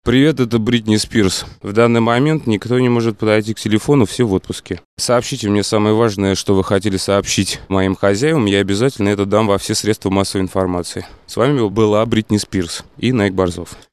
** Приветствующие сообщения для автоответчика **